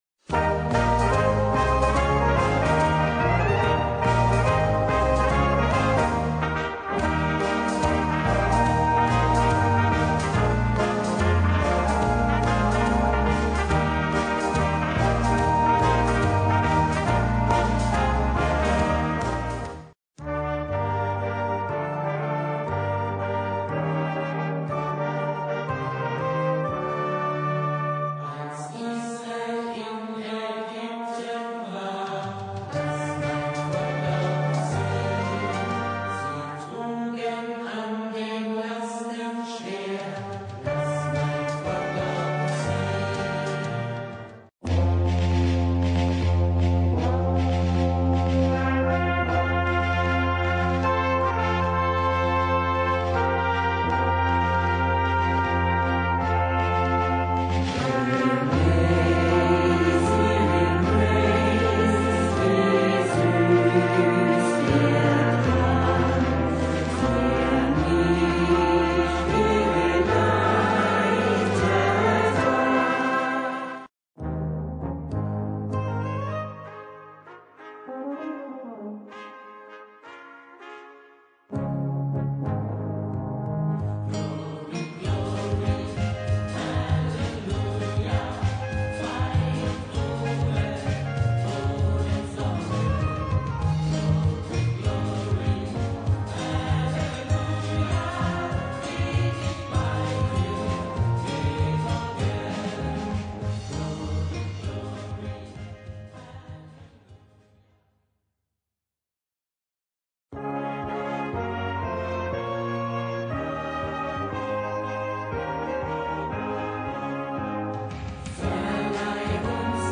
Gattung: Messe mit Gesang
Besetzung: Blasorchester